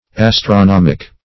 Astronomic \As`tro*nom"ic\, a.